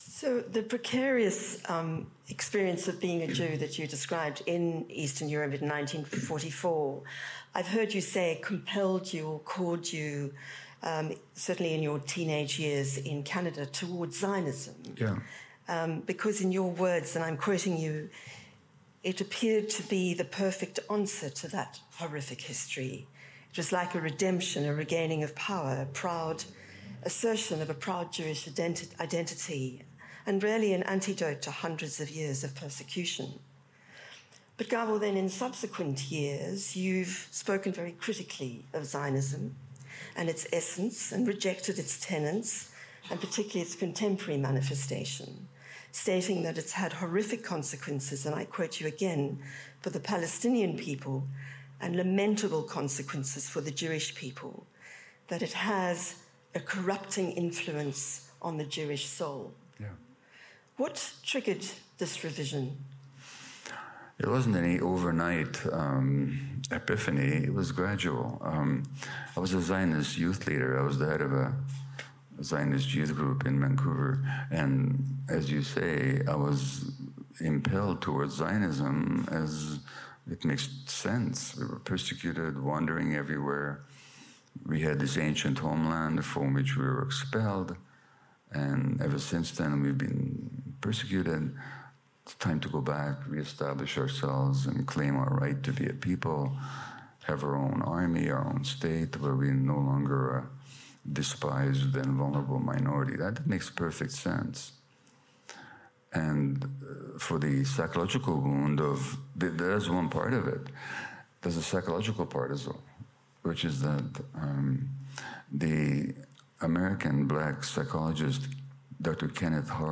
Gabor Mate Jewish Council of Australia excerpt.mp3